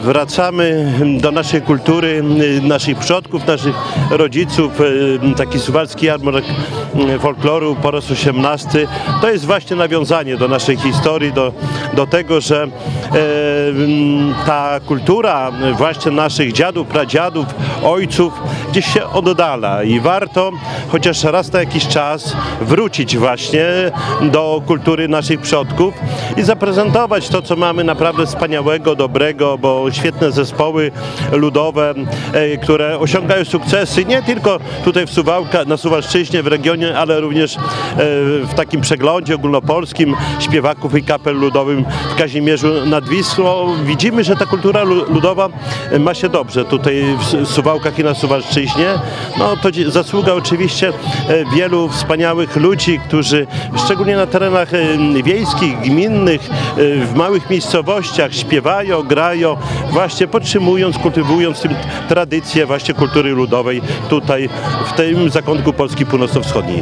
– Jarmark wpisał się już w tradycję naszych miejskich imprez – mówi Czesław Renkiewicz, prezydent Suwałk.
Czesław-Renkiewicz-prezydent-suwałk-o-jarmarku.mp3